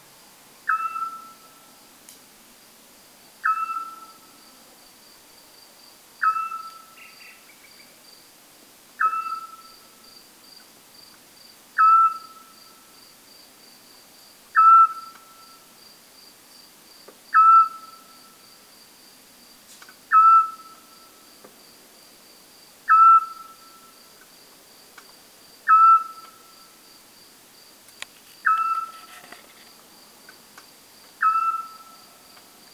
Se trata de un sonido aflautado “tiuuu”, repetido regularmente en intervalos de aproximadamente 2 segundos, que se asemeja al sonar de los submarinos.
Canto-Autillo-europeo-Otus-scops.wav